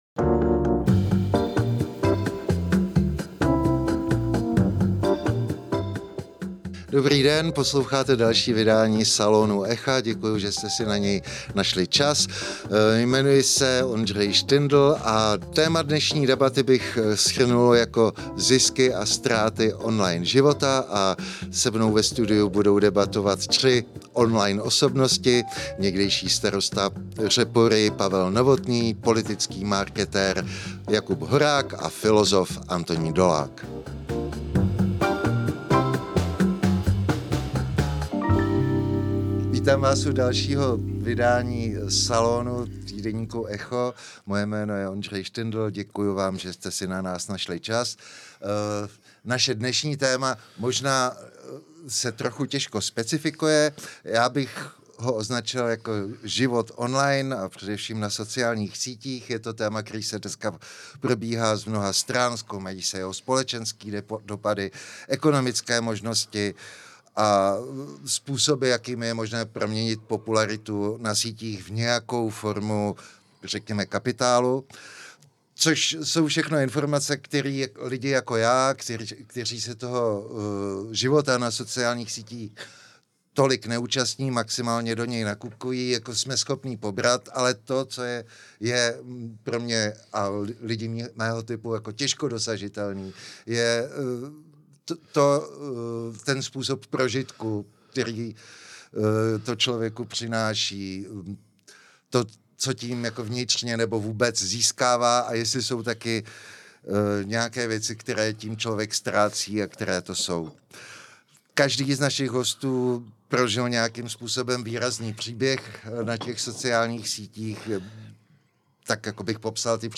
Účastníci se shodli na vzájemném tykání, debata to byla živá a impulzivní, občas v ní padaly nepublikovatelné výrazy, ty nejsilnější jsme „zcenzurovali“.